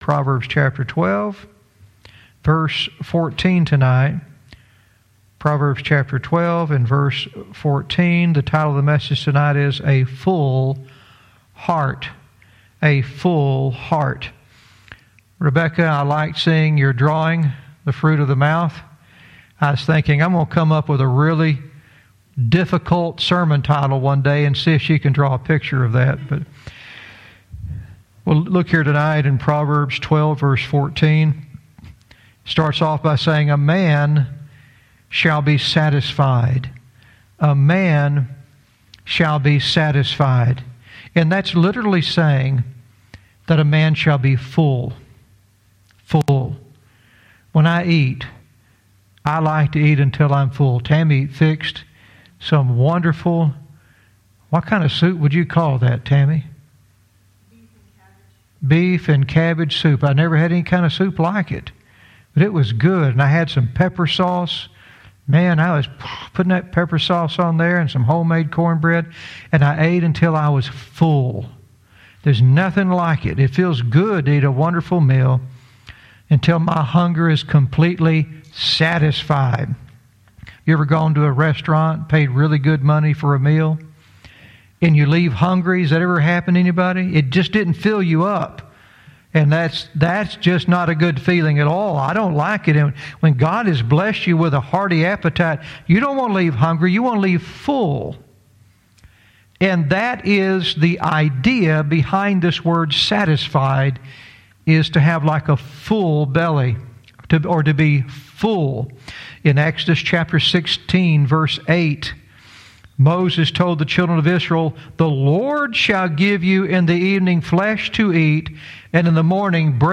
Verse by verse teaching - Proverbs 12:14 "A Full Hear"